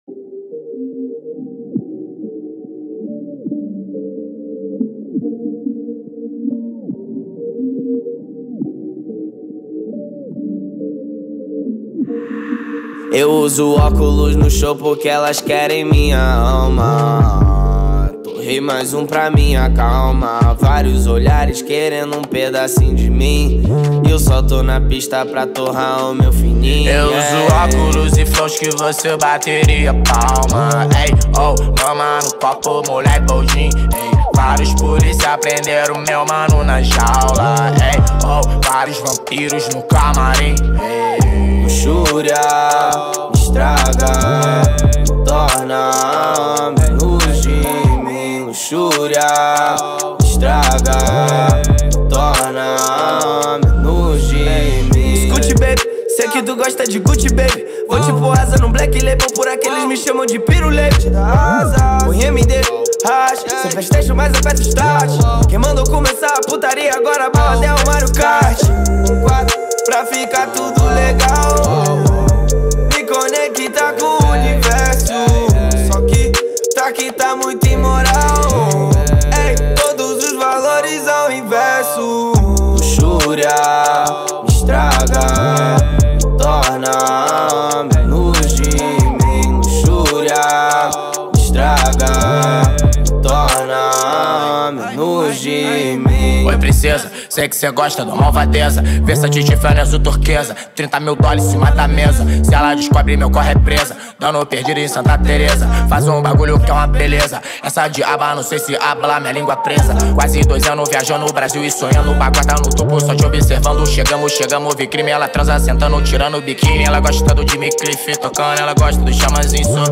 2024-07-24 15:58:44 Gênero: Trap Views